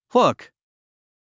発音
húk　フゥク